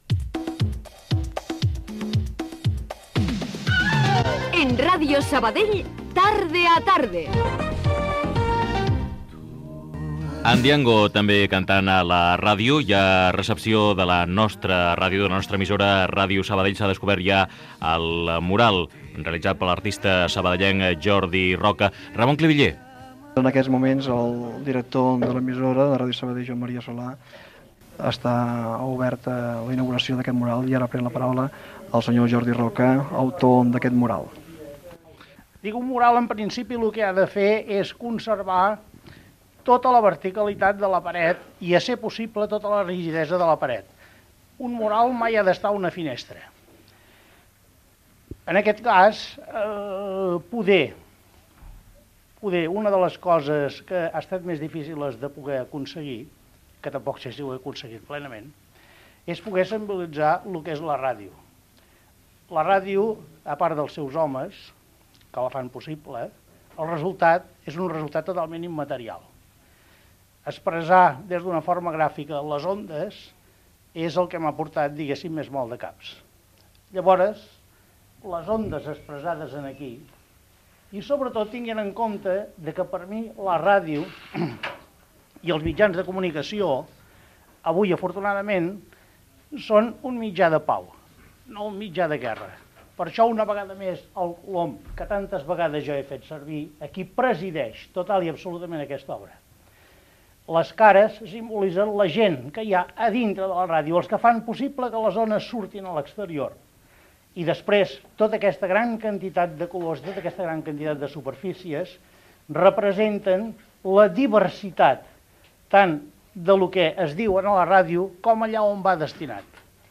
Edició especial amb motiu de la remodelació dels estudis de Ràdio Sabadell. Indicatiu del programa.